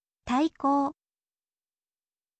taikou